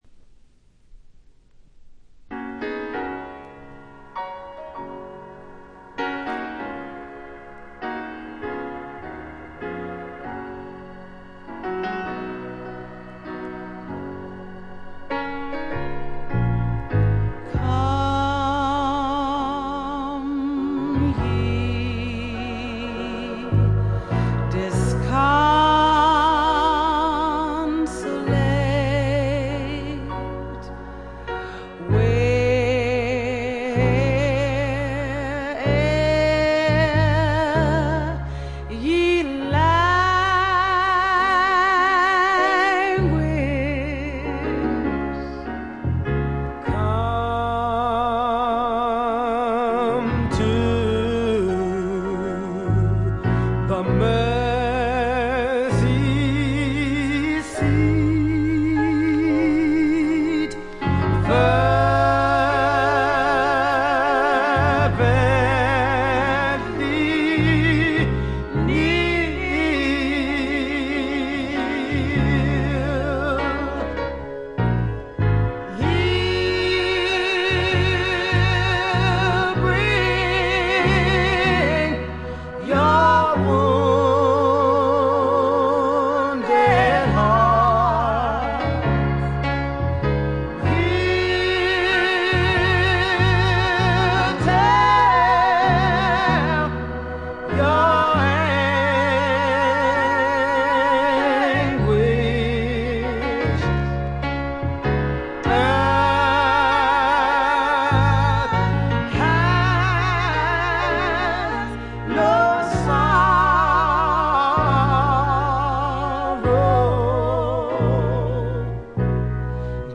部分試聴ですが、ほとんどノイズ感無し。
頂点を極めた二人の沁みる名唱の連続で身体が持ちませんね。
試聴曲は現品からの取り込み音源です。